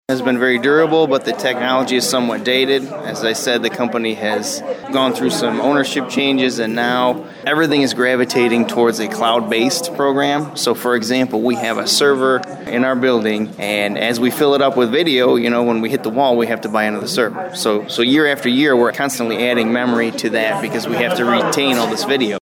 Chief Vince Emrick spoke with WLEN News after the City Commission voted unanimously to support him looking into the grant. He said the equipment his department uses now is good, but is becoming outdated…